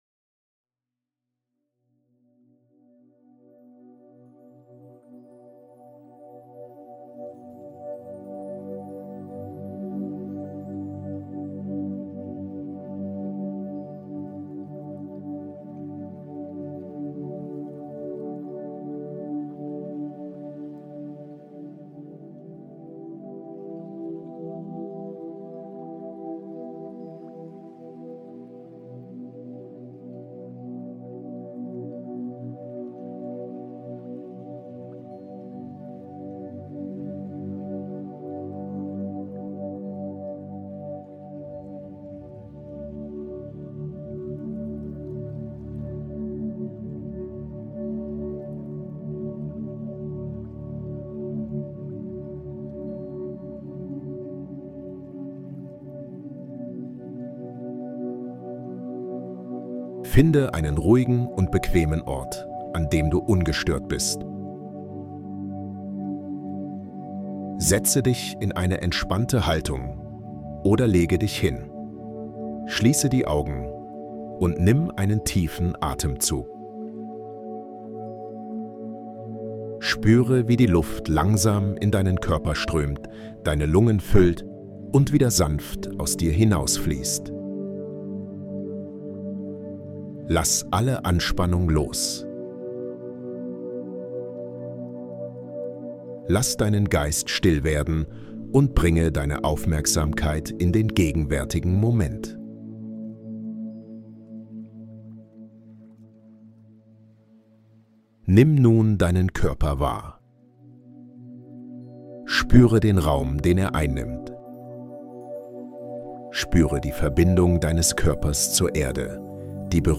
Geführte Meditation - Die Identifikation mit dem Körper lösen